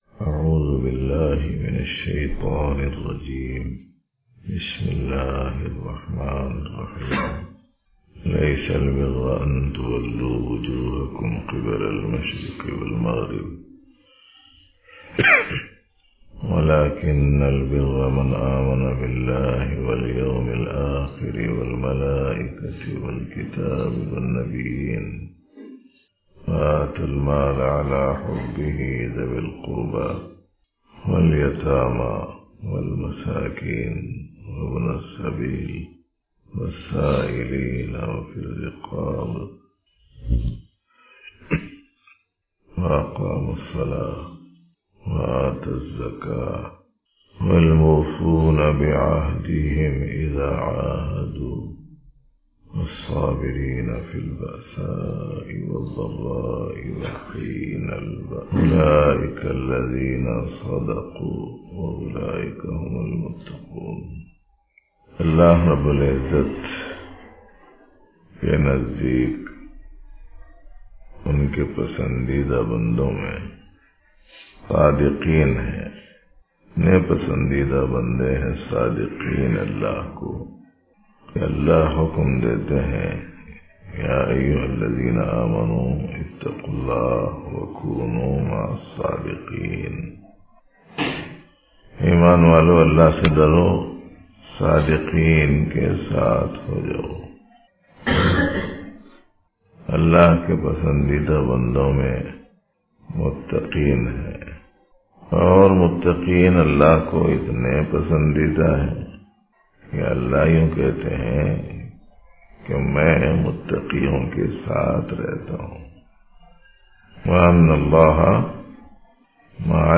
Dars e Tasawwuf in Itikaf